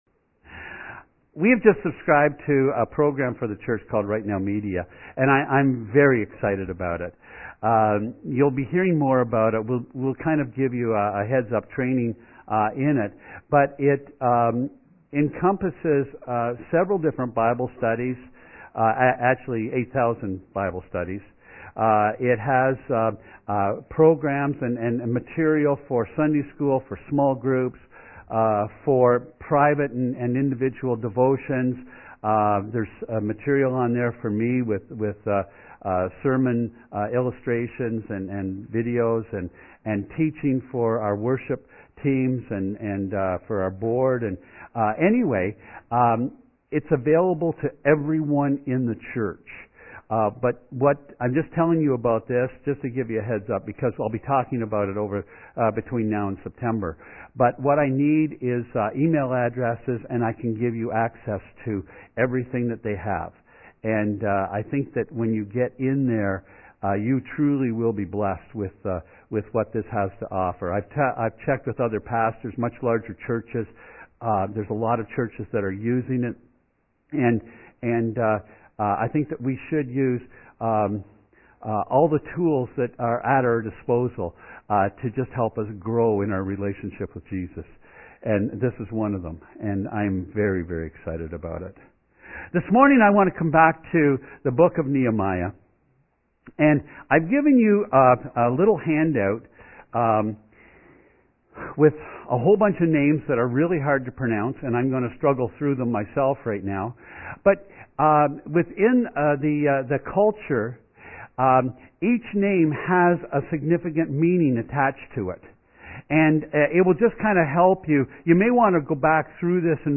Sermons | Peace River Alliance Church